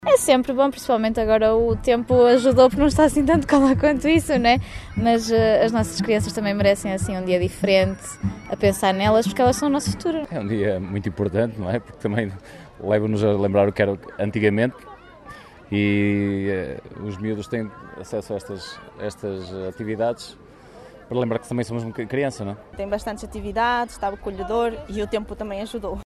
Os pais aplaudem a iniciativa: